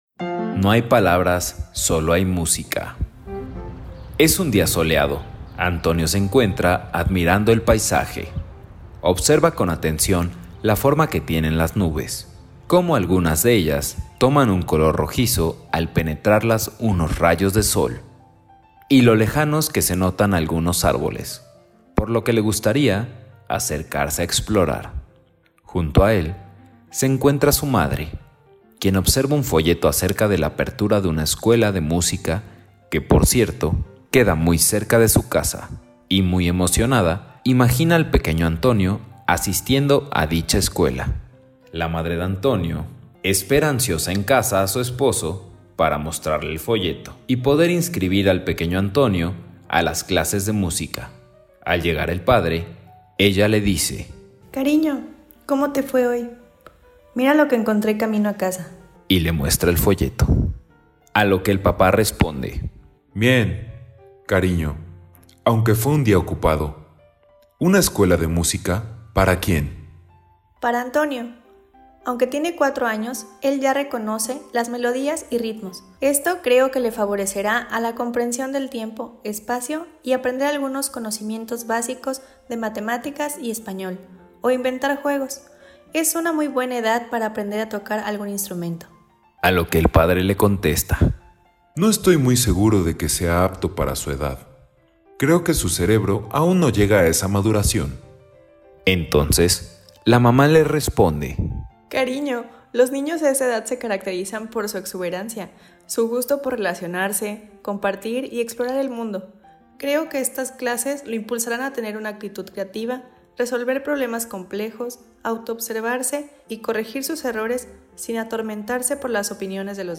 Cuento "No hay palabras, sólo canciones"